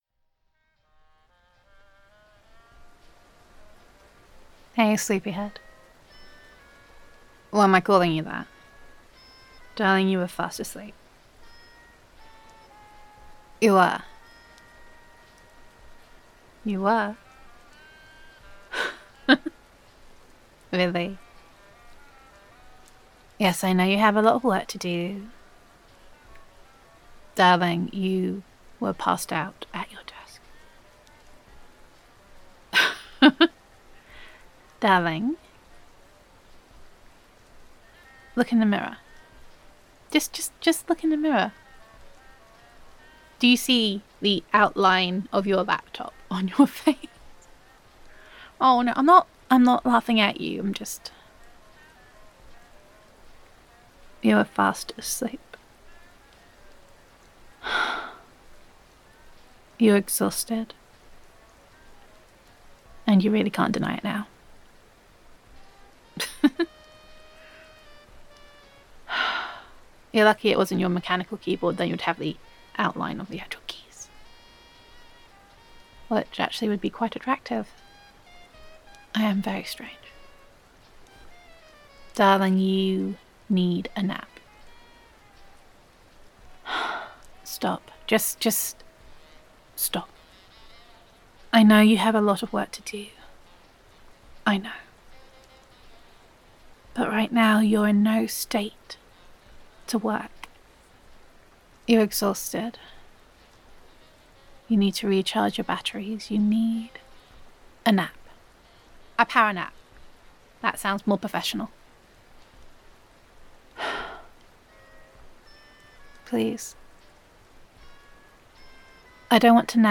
[F4A] Snuggle Up [Getting Some Kip][Exhaustion][Falling Asleep at Your Desk][Care and Concern][Enforced Snuggling and Naps][Girlfriend Roleplay][Time for a Nap][Loving][Comfort][Gender Neutral][Your Concerned Girlfriend Makes You Take a Nap]